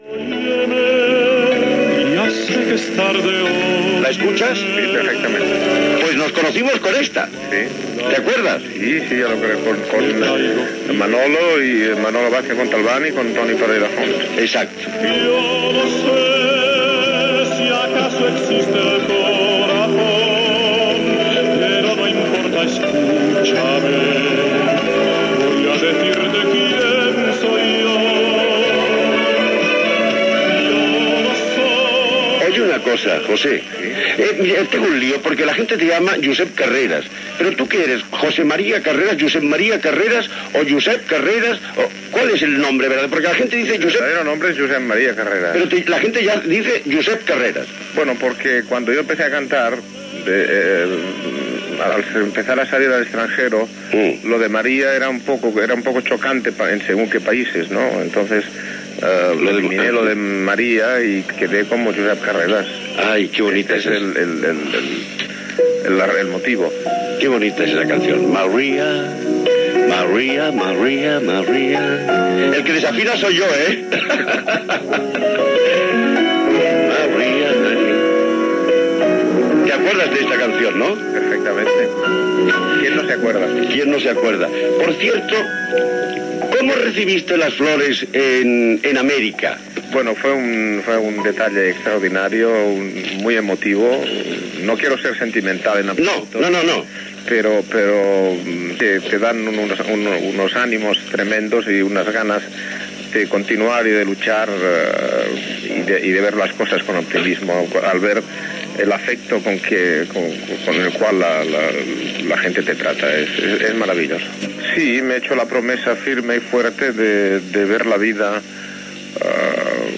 Entrevista al tenor Josep Carreras.